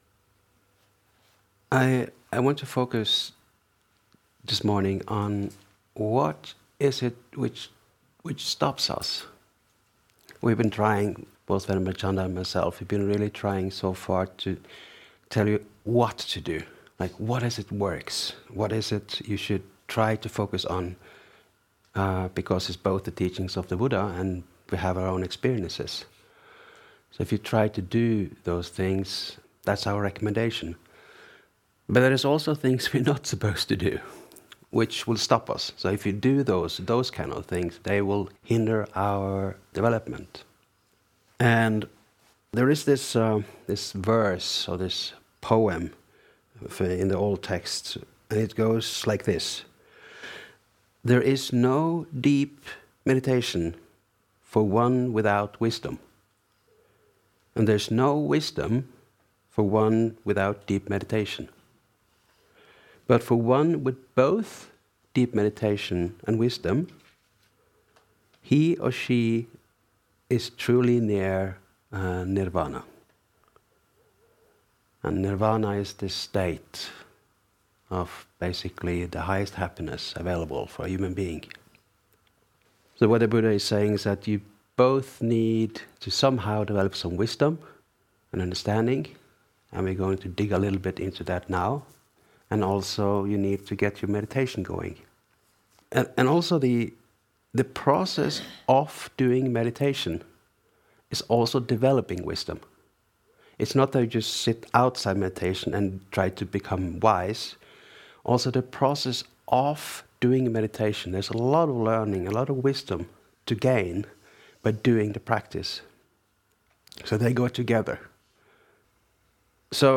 We can waist lots of time, doing the same things over and over again in meditation, if we don't sometimes pull back and look at what we are doing. Sometimes analysis is important, and for example using the framework of the five hindrances that stops our development. In this talk the focus is the hindrances of sensory desire, ill will, sloth and torpor, restlessness and remorse and doubt.